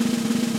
soft-sliderslide.ogg